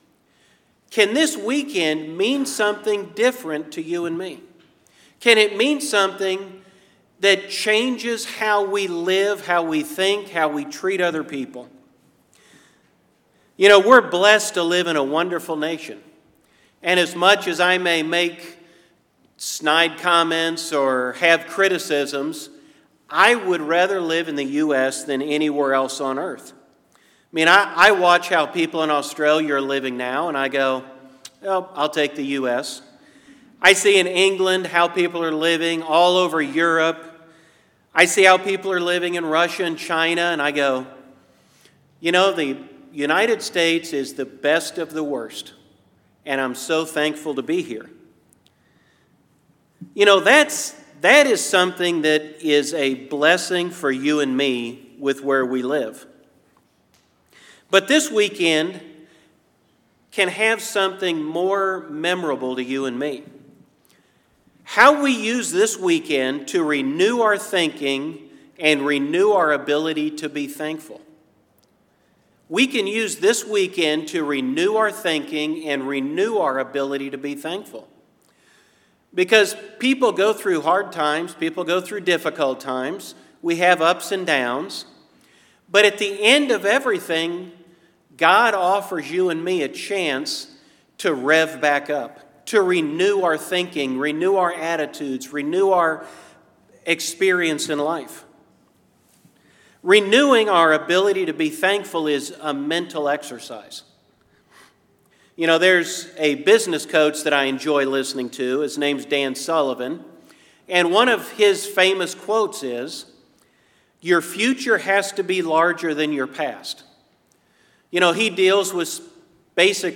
Sermons
Given in Columbus, OH